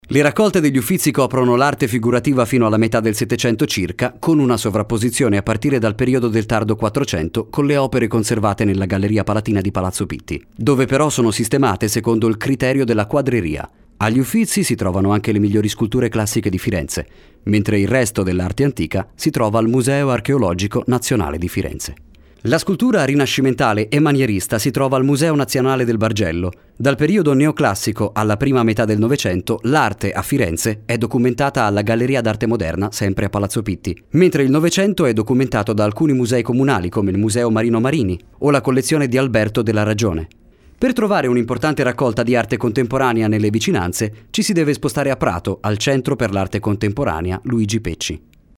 voce allegra, profonda ed interessante, da giovane adulto disponibile per le vostre produzioni
Kein Dialekt
Sprechprobe: Industrie (Muttersprache):